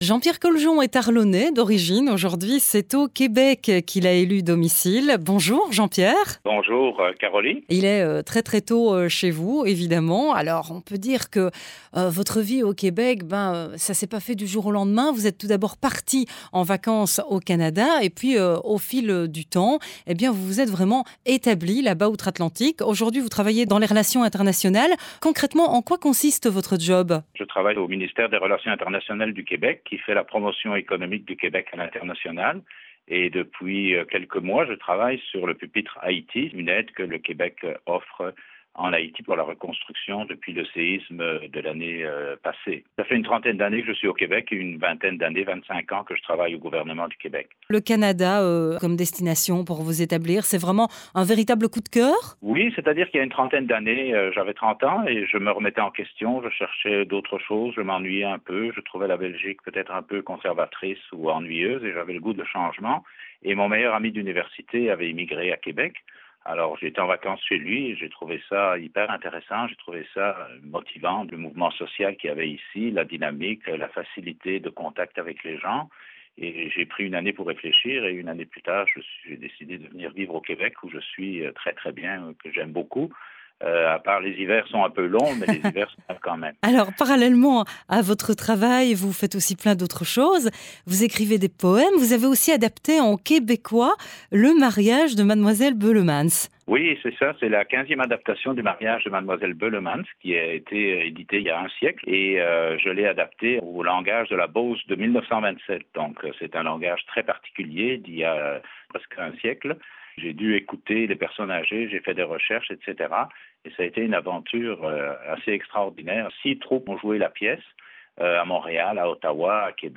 Entrevue